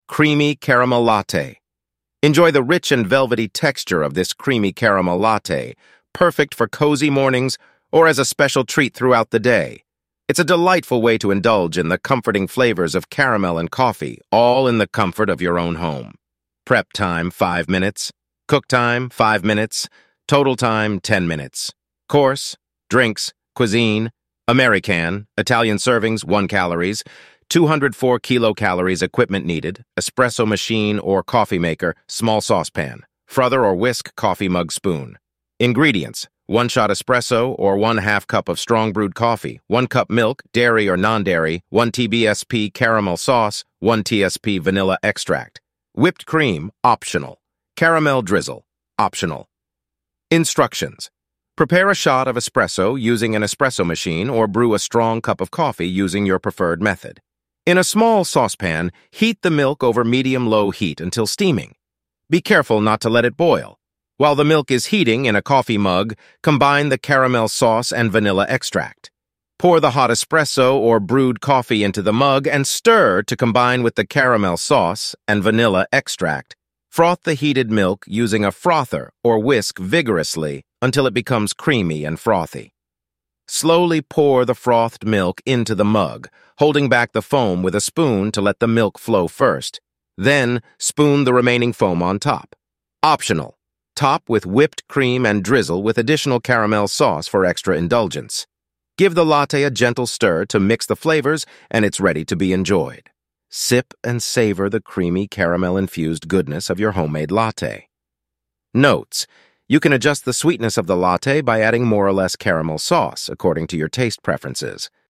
Recipe Audio
synthesized_audio.mp3